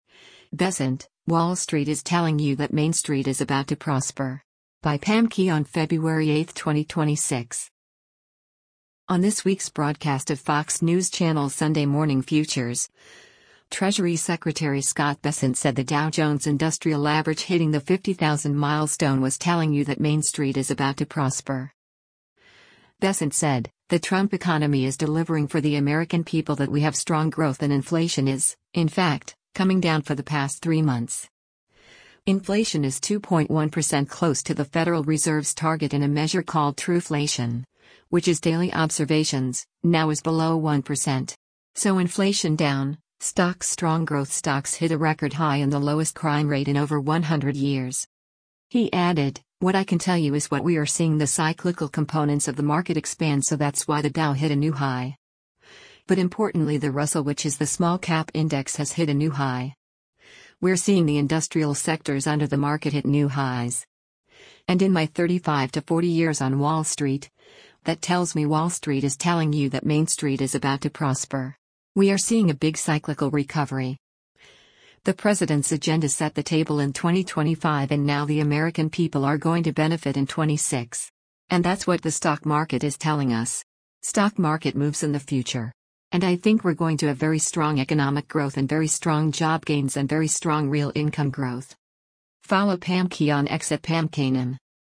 On this week’s broadcast of Fox News Channel’s “Sunday Morning Futures,” Treasury Secretary Scott Bessent said the Dow Jones Industrial Average hitting the 50,000 milestone was “telling you that Main Street is about to prosper.”